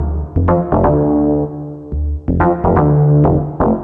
cch_bass_hardy_125_Dm.wav